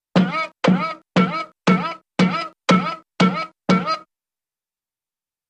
Object Bounces ( I.e. Pogo Stick Or Hopping ) 8x ( Jews Harp & Drum ).